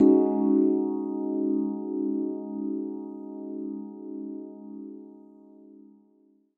Rhodes Steveland 1.wav